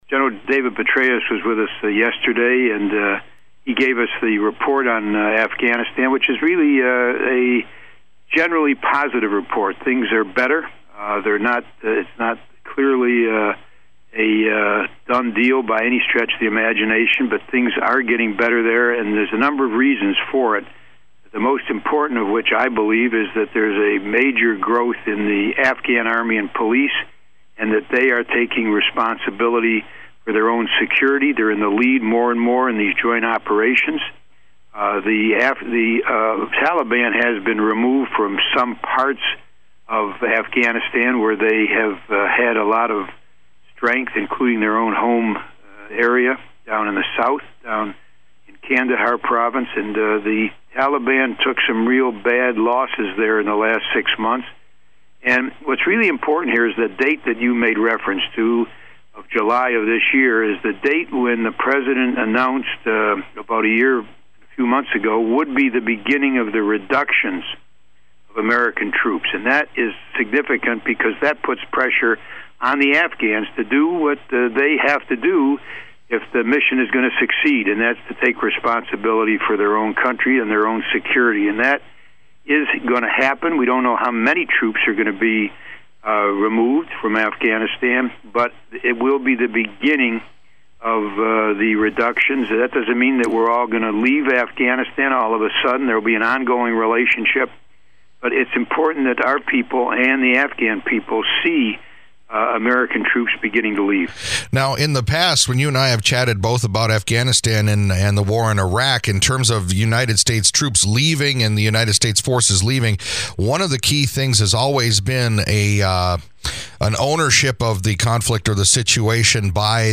Michigan’s Senior U.S. Senator Carl Levin called in this morning to chat about a hearing that was held yesterday in Washington, D.C. by Senate Armed Services Committee about the future plans for U.S. military involvement in Afghanistan.